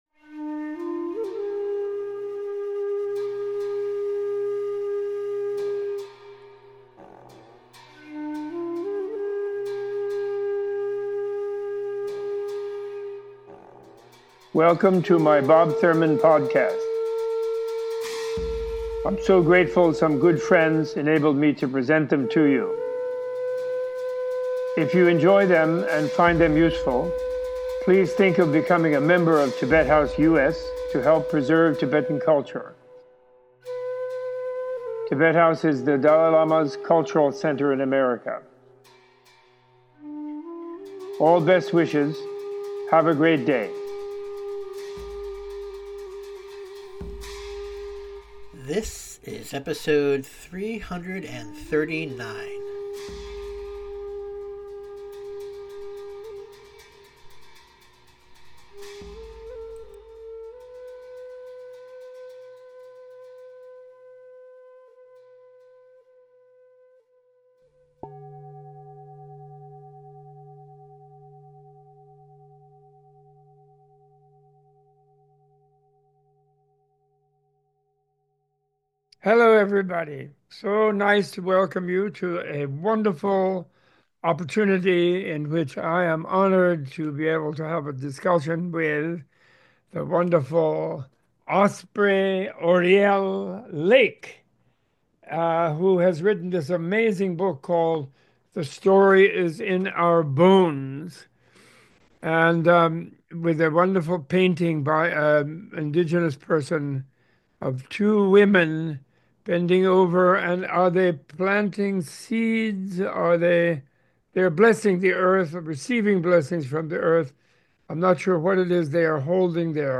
A Tibet House US Menla Conversation